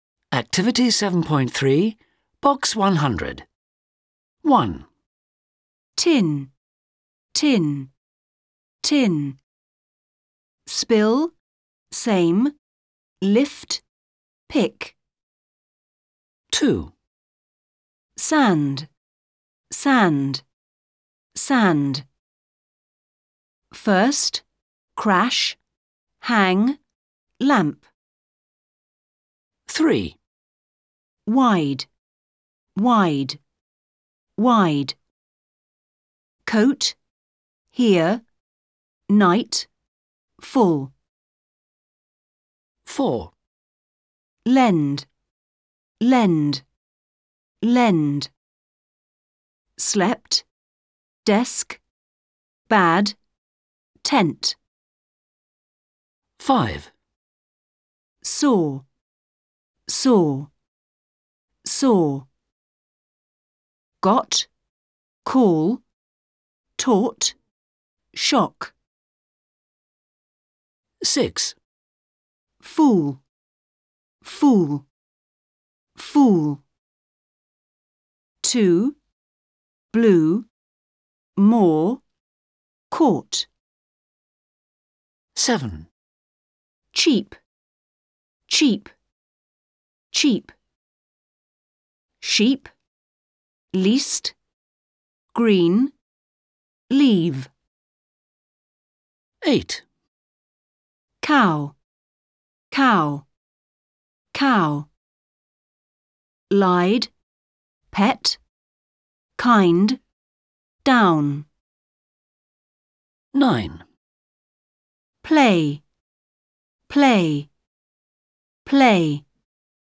Testing receptive skills - vowels.wav